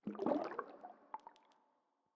Minecraft Version Minecraft Version snapshot Latest Release | Latest Snapshot snapshot / assets / minecraft / sounds / ambient / underwater / additions / driplets2.ogg Compare With Compare With Latest Release | Latest Snapshot
driplets2.ogg